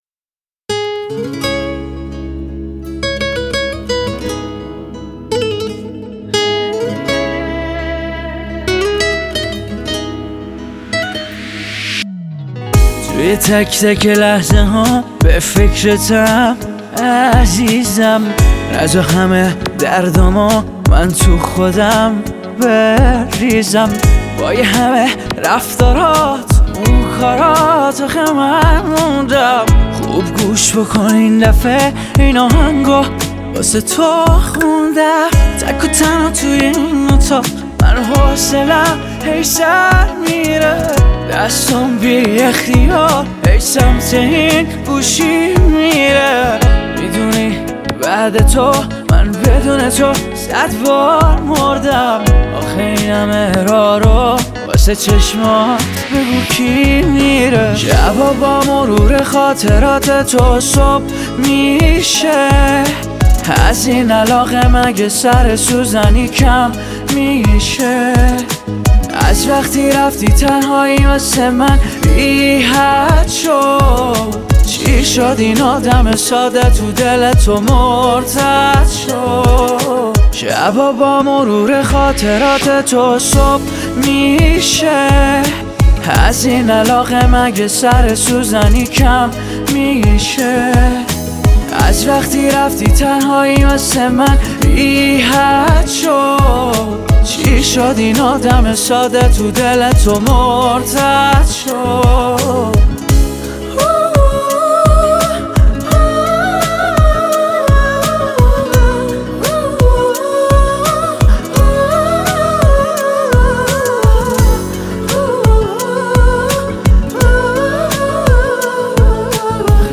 آهنگهای پاپ فارسی
موزیک بی کلام